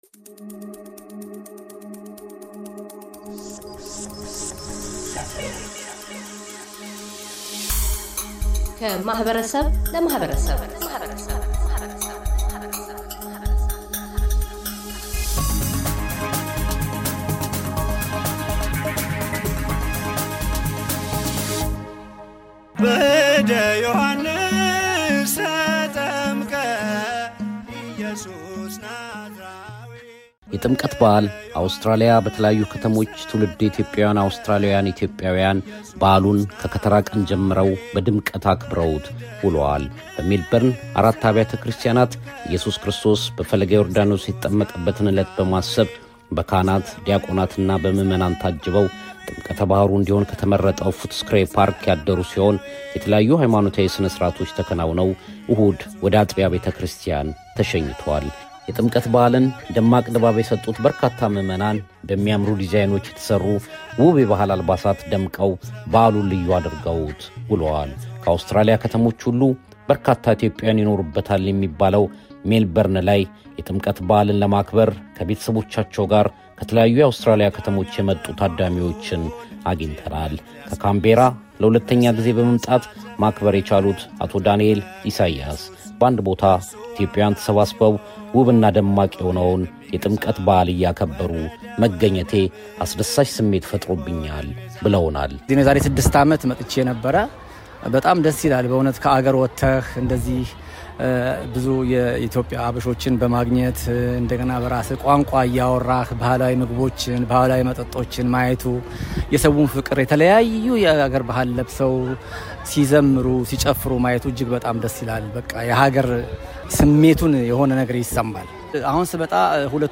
በዓለ ጥምቀት በሀገረ አውስትራሊያ ሜልበርን በድምቀት ተከብሮ አልፏል። የሜልበርን ነዋሪዎችን ጨምሮ ከተለያዩ የአውስትራሊያ ክፍለ አገራት የመጡ ኢትዮጵያውያን የበዓሉን መንፈሳዊና ማኅበራዊ ፋይዳዎች አስመልክተው አተያዮቻቸውን ያንፀባርቃሉ።